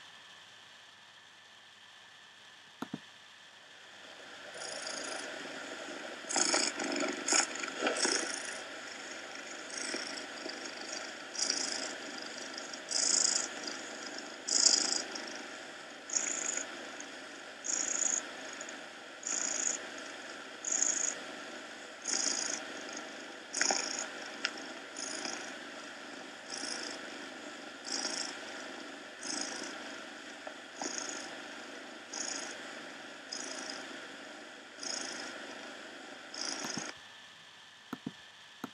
fusa_di_gatto.m4a